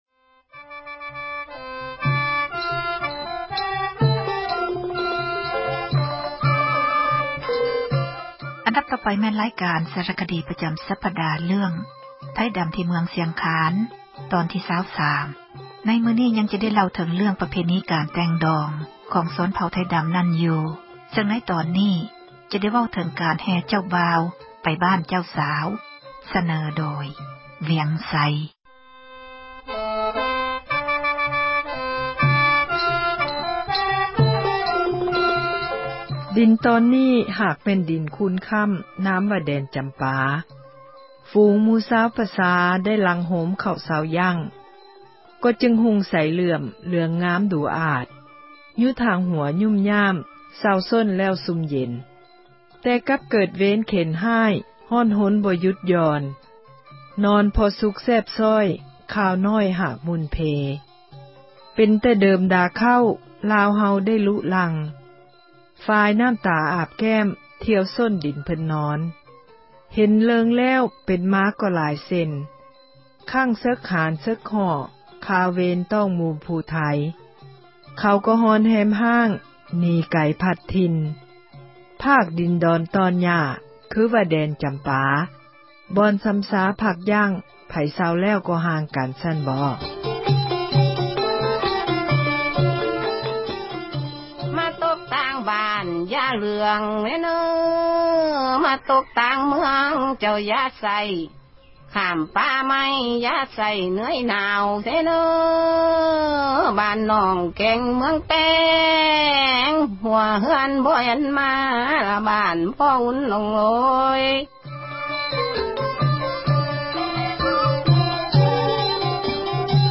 ສາຣະຄະດີ ເຣື້ອງ ”ໄທດຳ ທີ່ເມືອງ ຊຽງຄານ” ໃນຕອນທີ 23 ຍັງຈະໄດ້ ເລົ່າເຖິງ ເຣື້ອງ ປະເພນີ ການແຕ່ງດອງ ຂອງຊົນເຜົ່າ ໄທດຳ ນັ້ນຢູ່ ແລະຈະໄດ້ ເວົ້າເຖິງ ການແຮ່ ເຈົ້າບ່າວ ໄປບ້ານ ເຈົ້າສາວ.